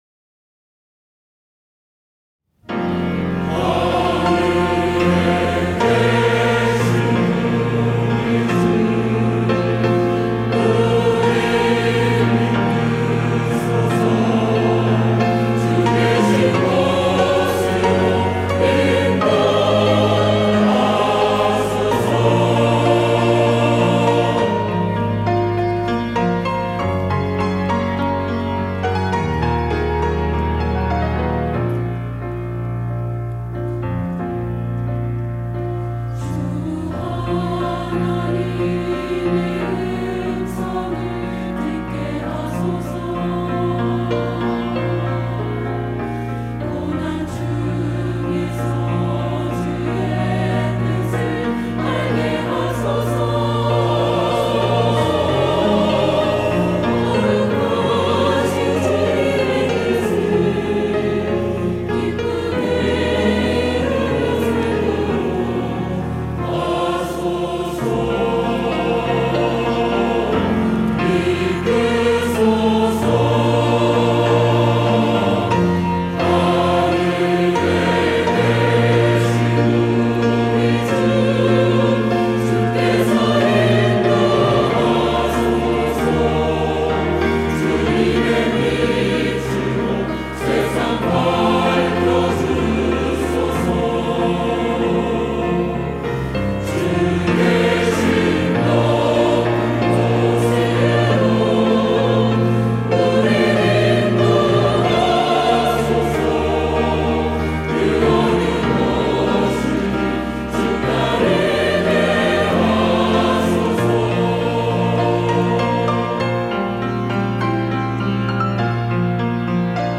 할렐루야(주일2부) - 하늘에 계신 우리 주
찬양대